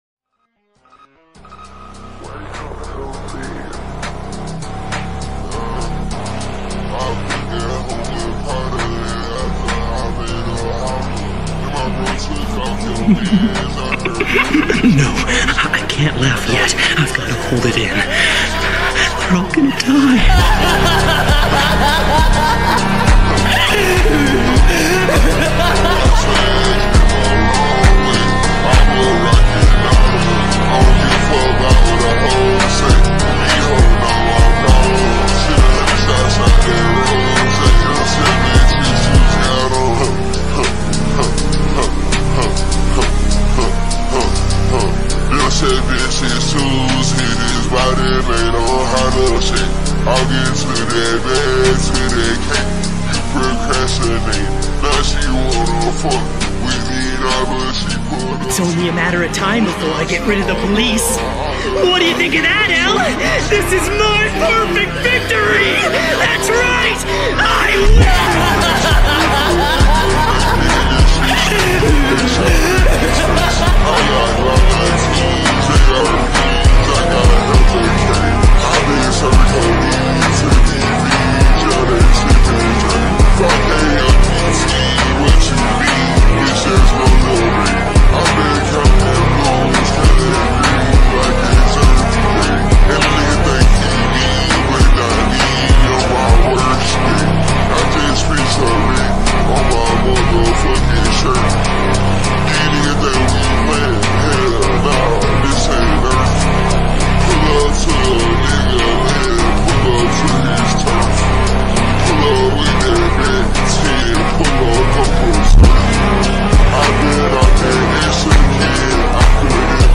Remix {slowed + reverb}